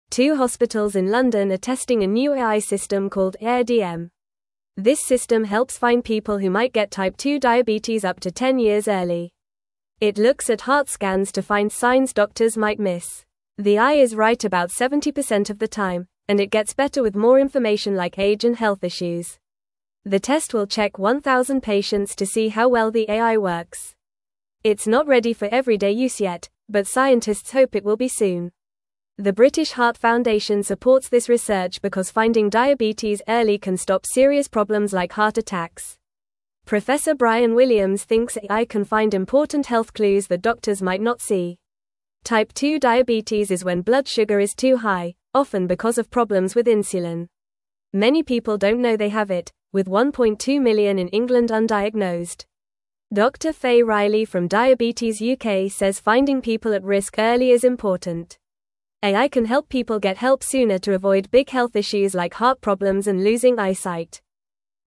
Fast
English-Newsroom-Lower-Intermediate-FAST-Reading-New-Computer-Helps-Find-Diabetes-Early-in-People.mp3